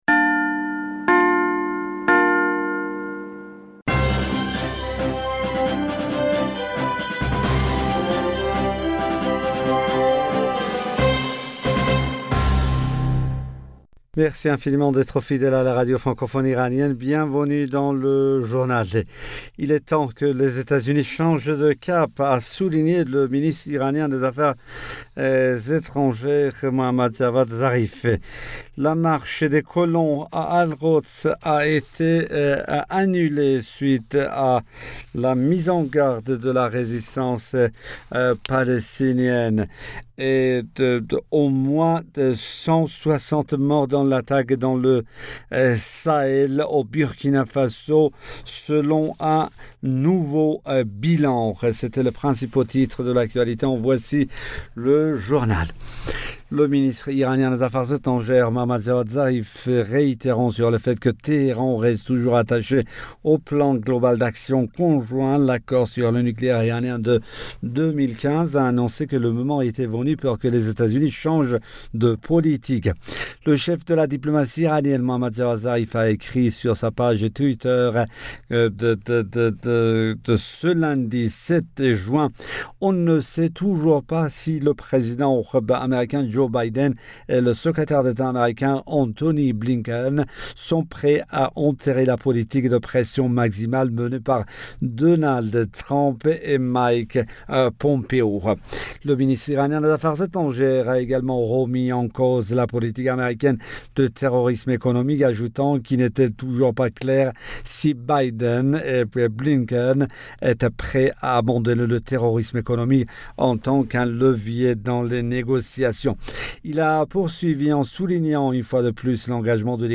Bulletin d'information du 08 Juin 2021